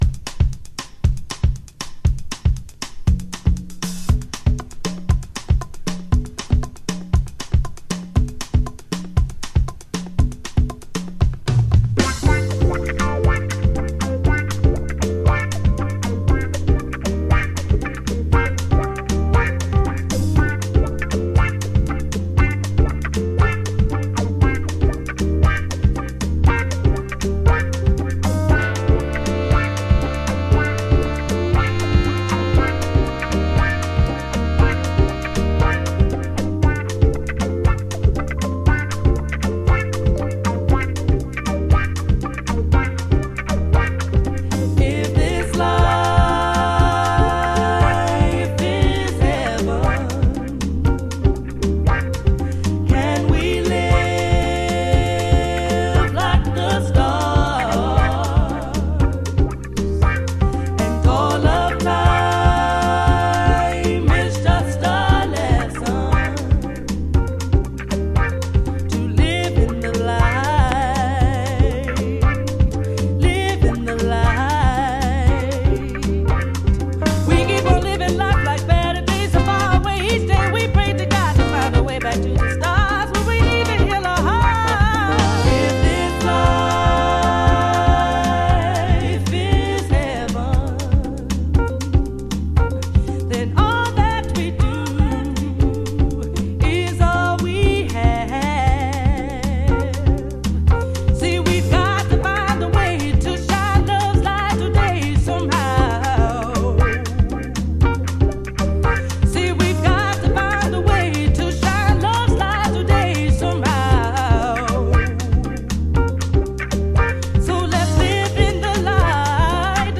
House / Techno
70年代のフリーフォームなブラックジャズを受け継ぐ